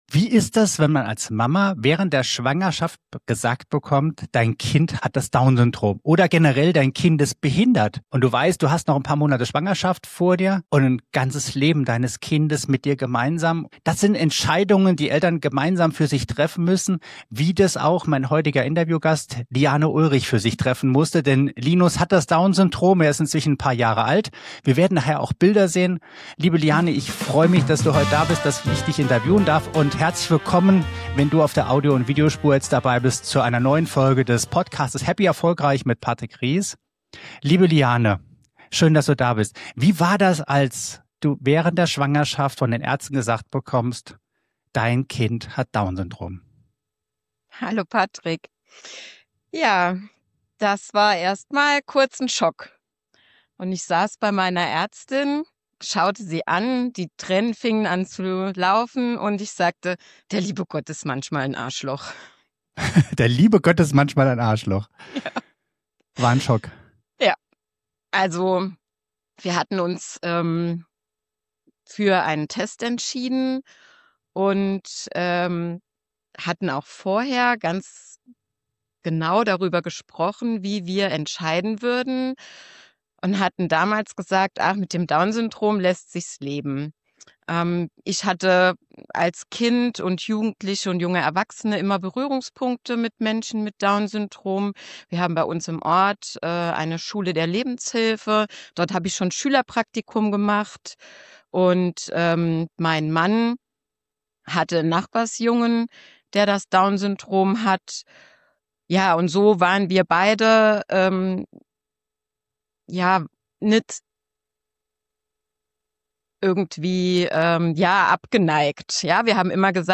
Interview ~ HappyErfolgReich